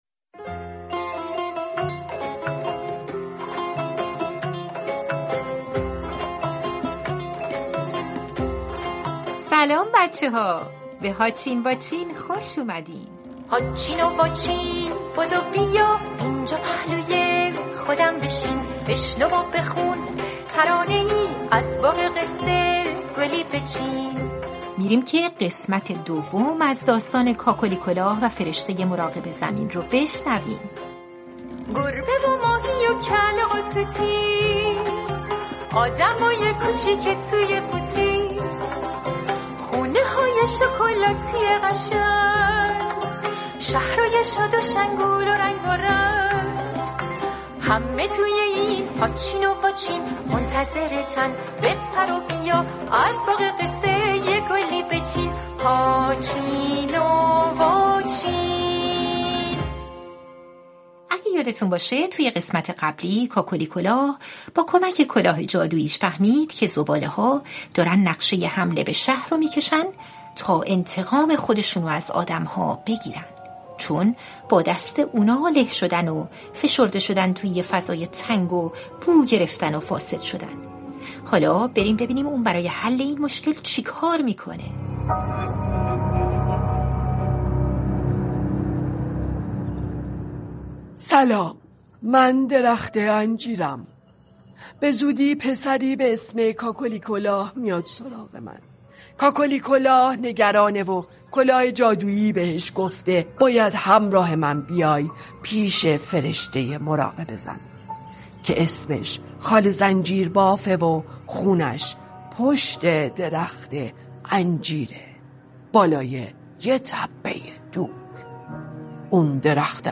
پادکست «هاچین واچین» کتاب صوتی از مجموعه داستان‌های کودکان است.